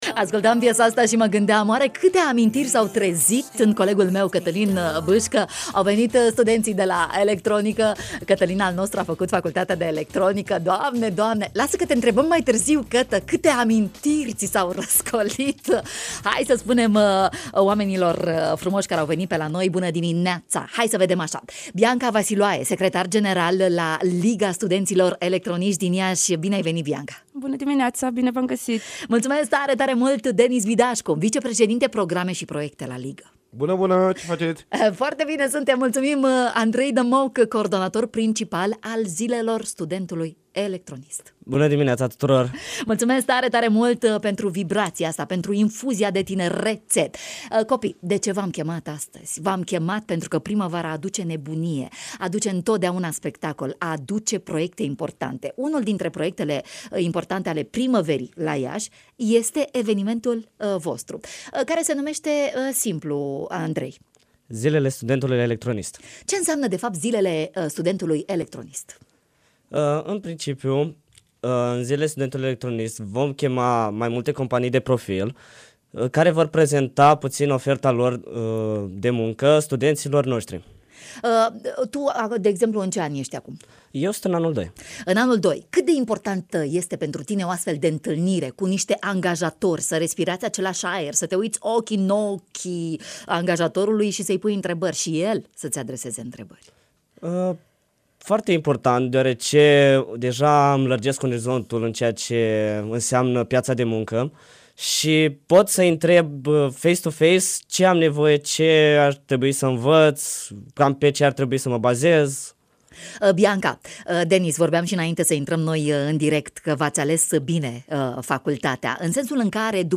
În matinalul de la radio România Iași ne-au vorbit despre eveniment